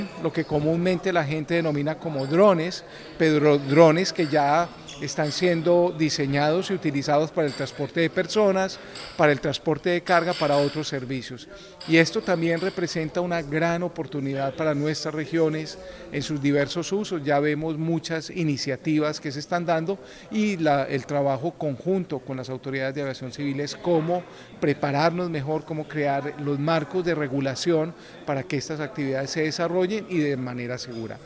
En entrevista con el Sr. Juan Carlos Salazar, Secretario General de la OACI, conocimos sobre las estrategias para hacer viables las prioridades del sector a nivel global con el apoyo conjunto de los estados, sobre todo los de la región de Norteamérica, Centroamérica y el Caribe.
corte-2-entrevista-aeronautica.mp3